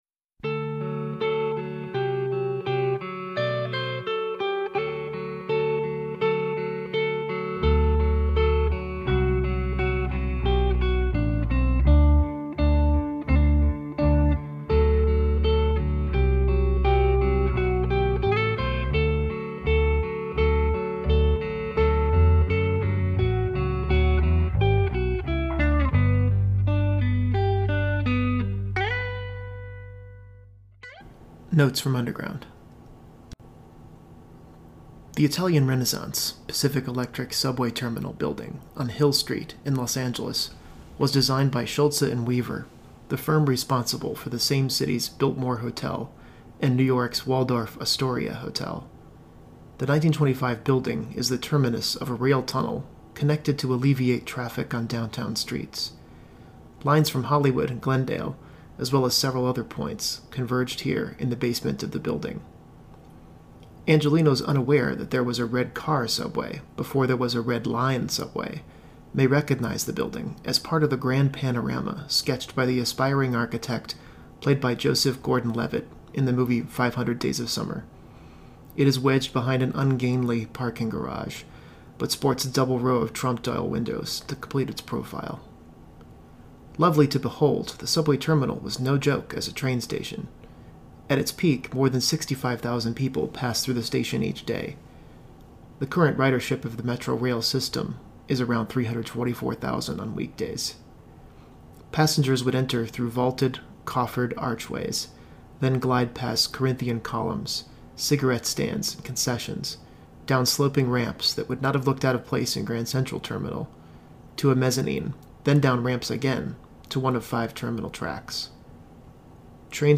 A tour of the abandoned Pacific Electric Subway Terminal in downtown Los Angeles.